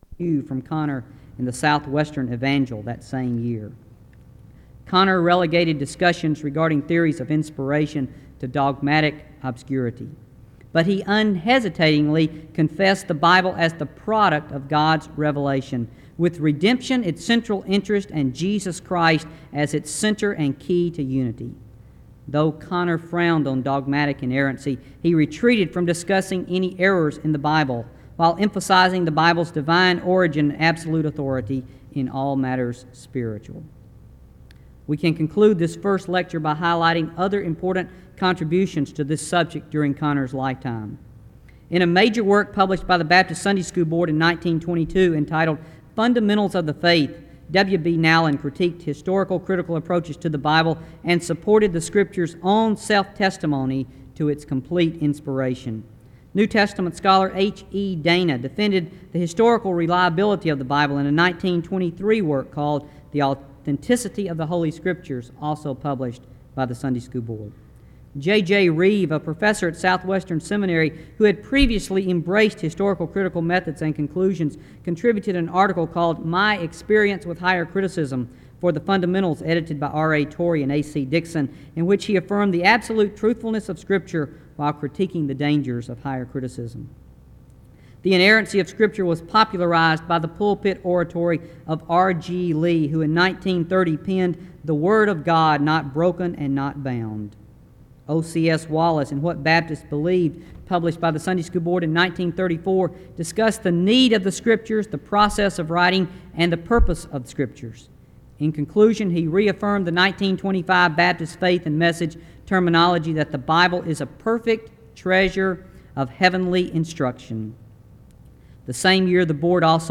SEBTS Page Lecture
SEBTS Chapel and Special Event Recordings